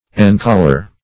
Encollar \En*col"lar\, v. t. To furnish or surround with a collar.